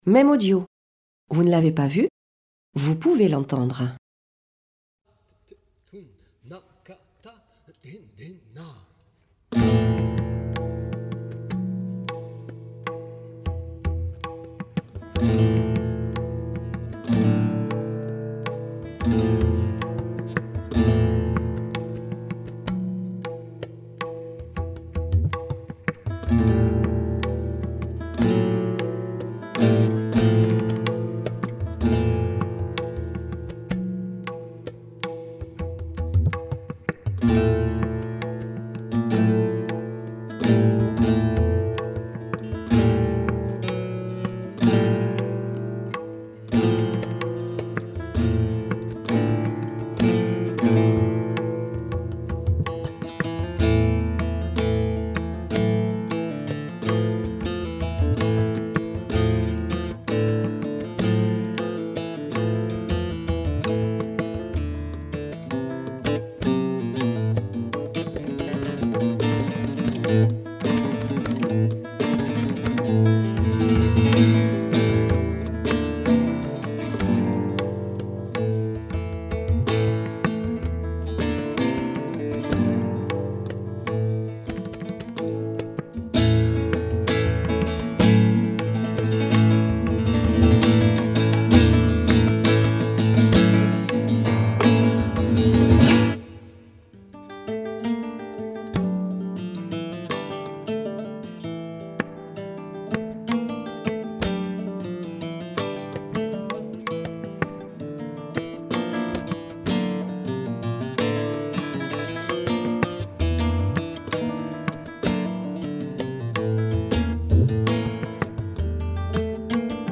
Concert
Bibliothèque d'étude et du patrimoine, Toulouse, le 3 octobre 2025
En partenariat avec le festival Cinespaña, un concert de Flamenco qui invite à la confrontation rythmique entre guitare flamenca et tabla indien).